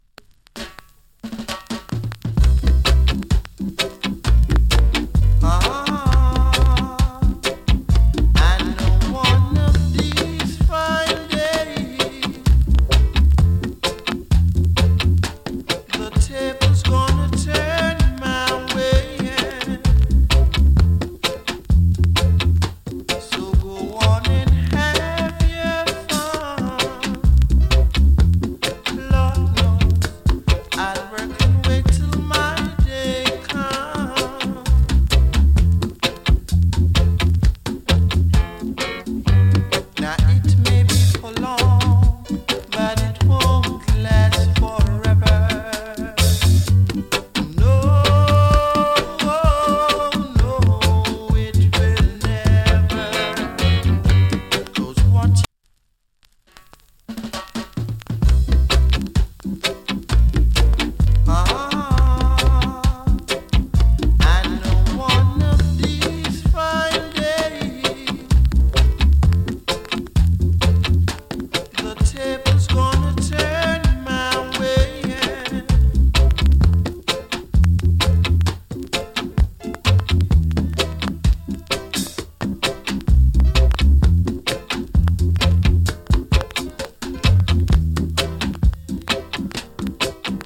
チリ、パチノイズ少々有り。B-SIDE はノイズ有り。
人気の哀愁 VOCAL REGGAE !!